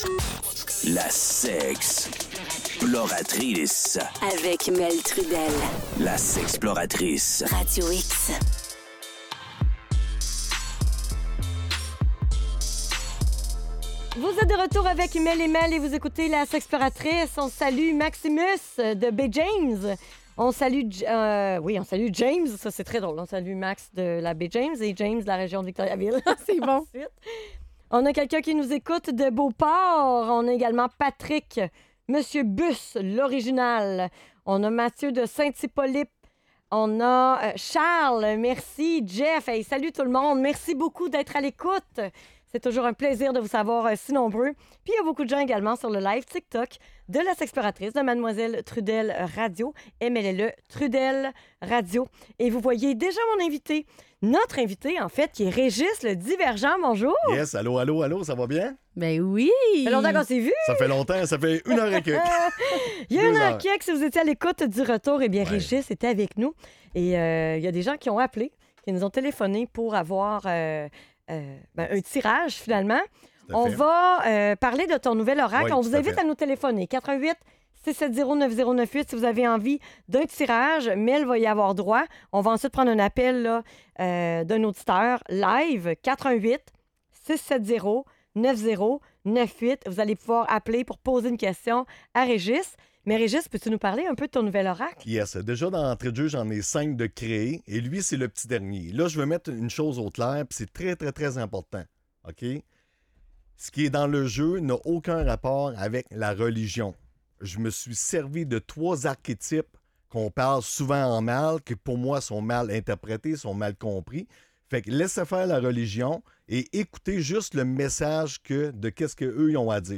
Les animateurs et les auditeurs interagissent en posant des questions sur la reprise de pouvoir et la lutte contre le syndrome de l'imposteur.